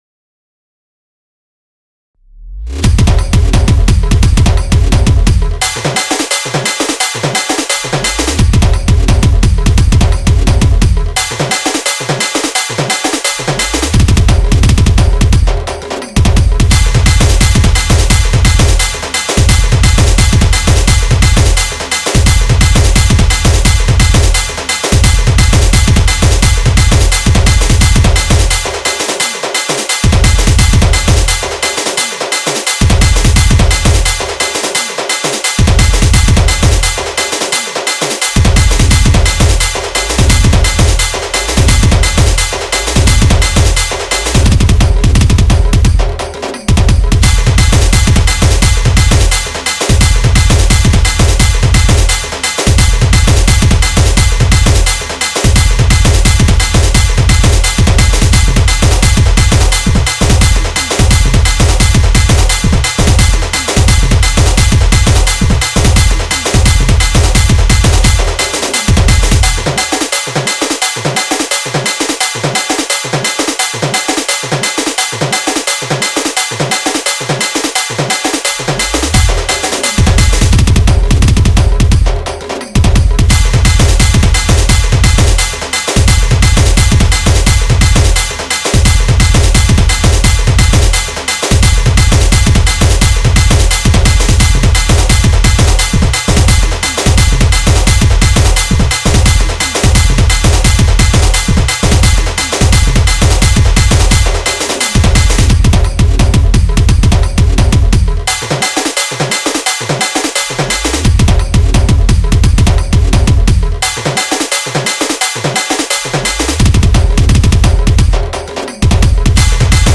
Instrumental Music And Rhythm Track Songs Download